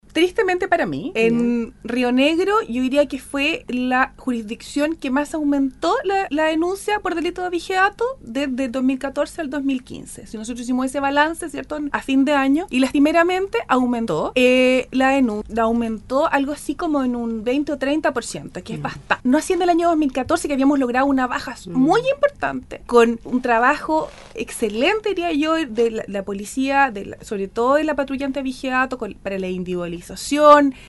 images LEYLA CHAIN, Fiscal Jefe del Ministerio Publico de Río Negro, en conversación con RADIO SAGO, confirmó que entre el año 2014 y el 2015 se produjo un aumento de los delitos de abigeato en los sectores rurales de su jurisdicción.